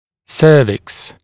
[ˈsəːvɪks][‘сё:викс]шея (часть тела), шейка (зуба)